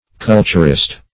culturist - definition of culturist - synonyms, pronunciation, spelling from Free Dictionary
Culturist \Cul"tur*ist\, n.